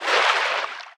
Sfx_creature_babypenguin_swim_glide_03.ogg